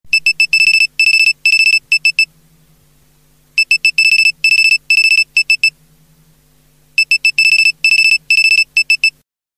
• Качество: 128, Stereo
Длинные и протяжные звуки, составляют слово о "Помогите"